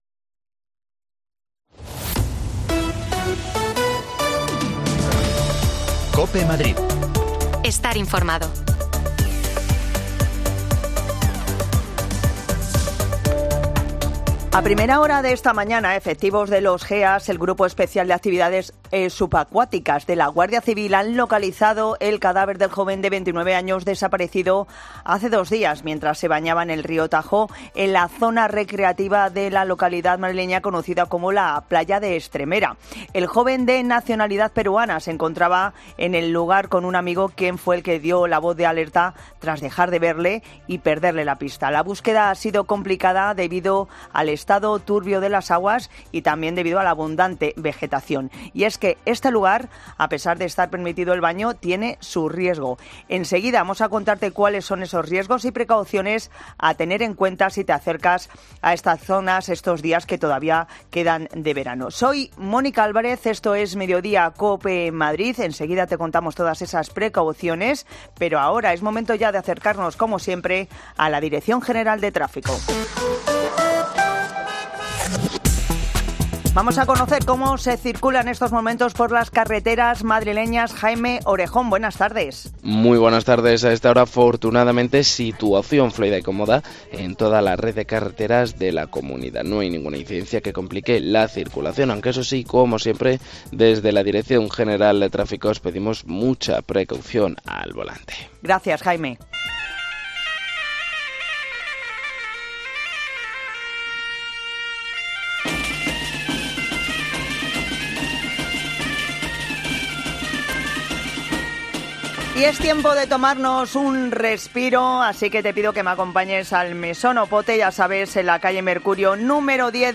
Las desconexiones locales de Madrid son espacios de 10 minutos de duración que se emiten en COPE, de lunes a viernes.
Te contamos las últimas noticias de la Comunidad de Madrid con los mejores reportajes que más te interesan y las mejores entrevistas, siempre pensando en el ciudadano madrileño.